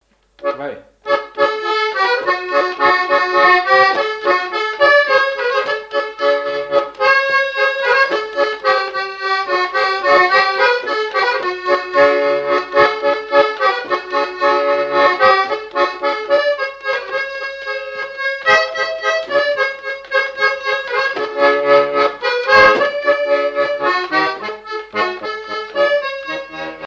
danse : valse
Répertoire et souvenir des musiciens locaux
Pièce musicale inédite